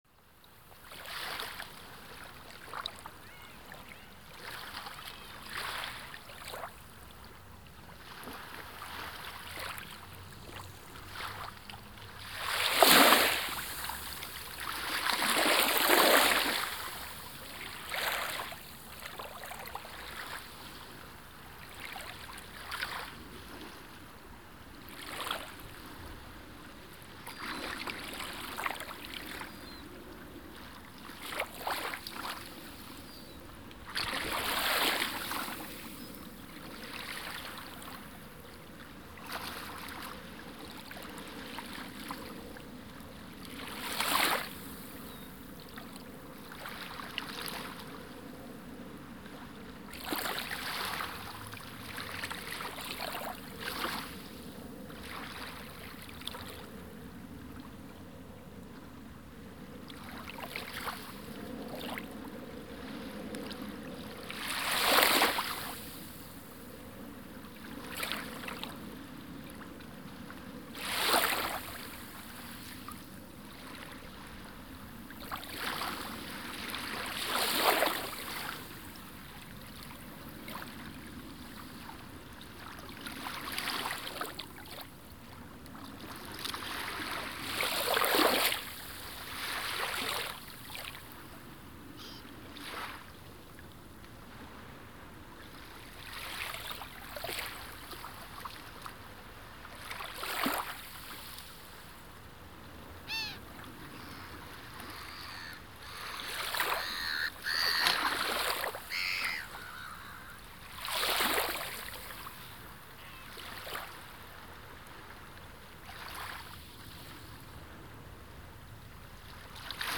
Sea, surf, waves 38845
• Category: Sound 5.1
• Quality: High